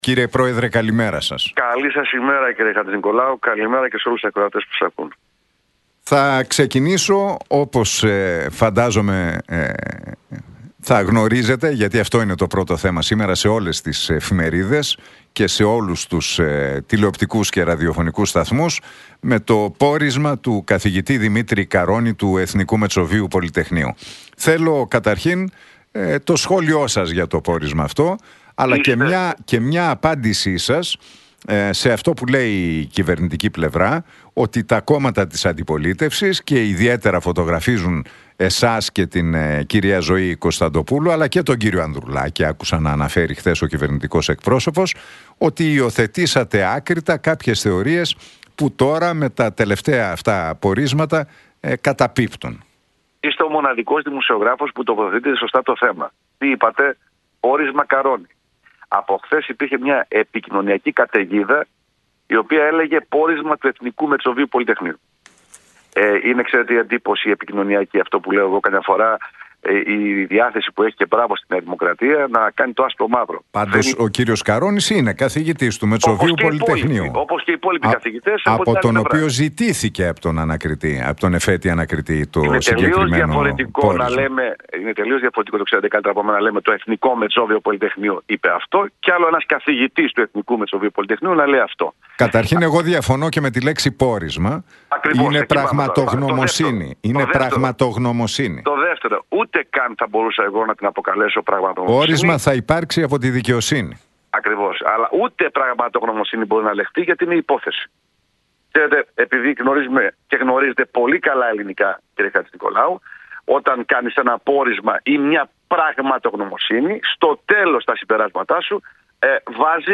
μίλησε ο Κυριάκος Βελόπουλος στον Realfm 97,8 και την εκπομπή του Νίκου Χατζηνικολάου.